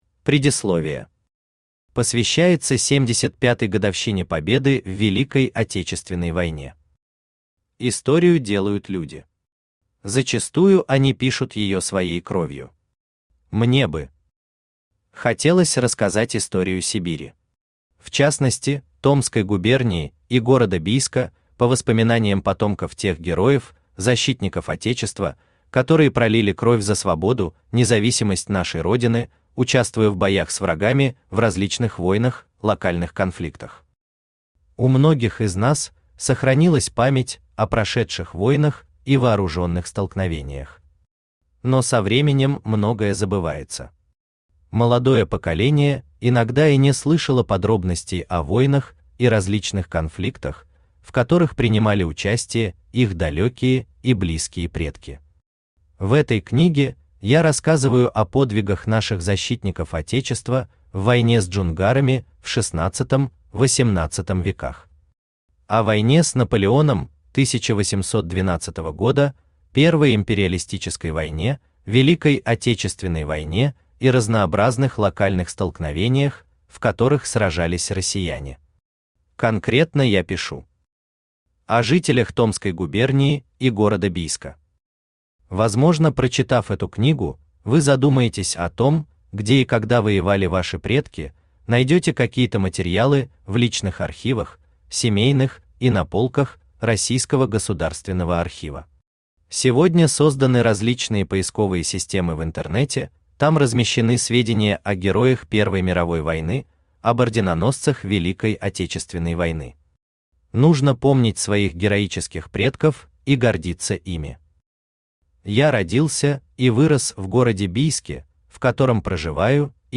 Аудиокнига Не погаснет огонь Памяти | Библиотека аудиокниг
Aудиокнига Не погаснет огонь Памяти Автор Виктор Евгеньевич Бабушкин Читает аудиокнигу Авточтец ЛитРес.